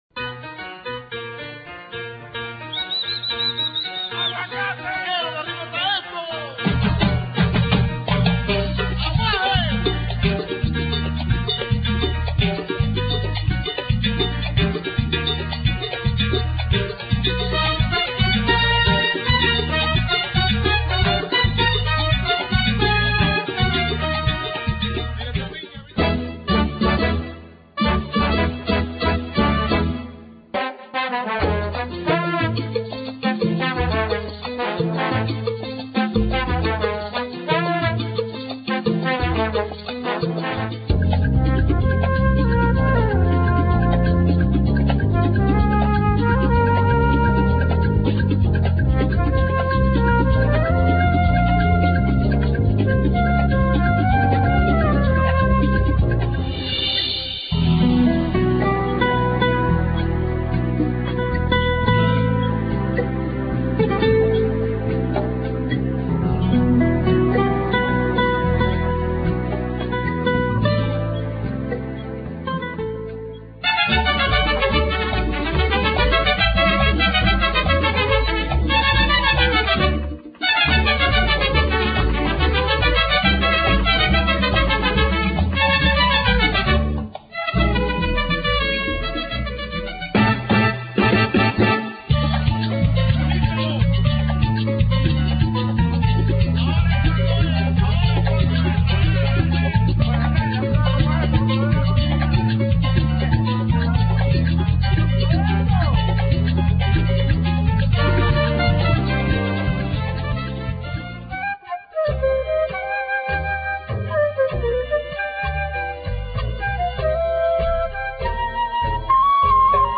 Latin Dance Collection